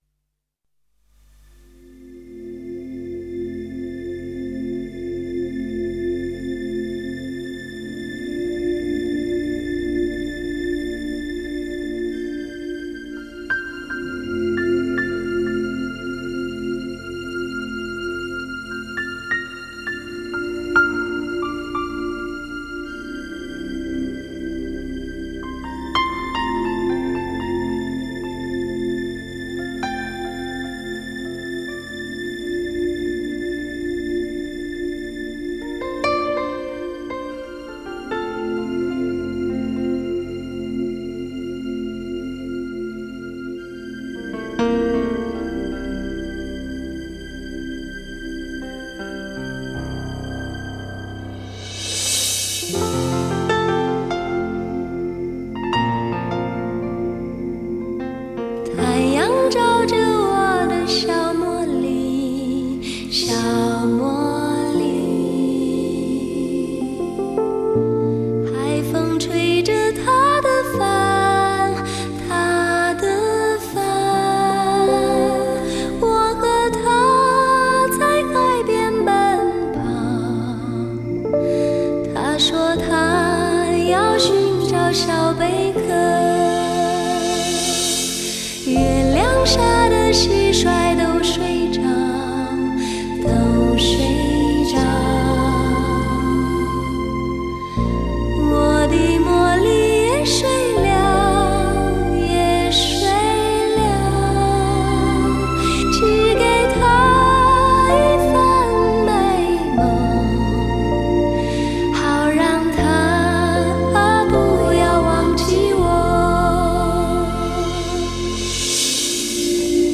如何寻找一份寂静与解脱……清纯、甜美的歌声，